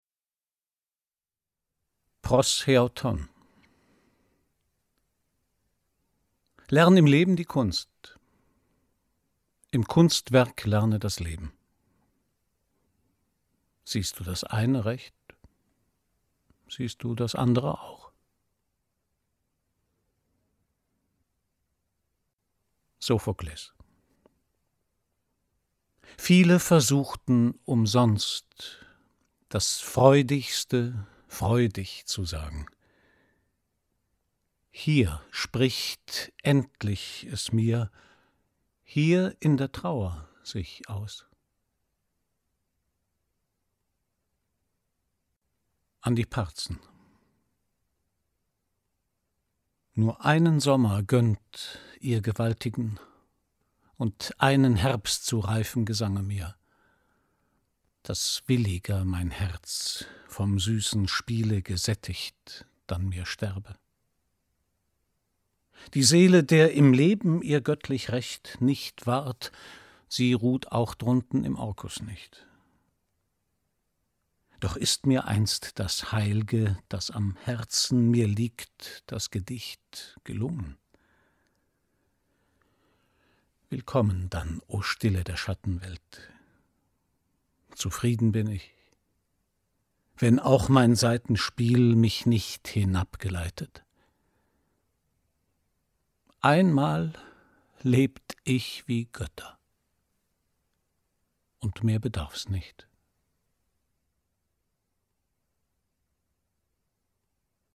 Das Zusammenspiel von Wort und Musik verleiht den Gedichten des großen Romantikers Hölderlin ungekannte Dimensionen.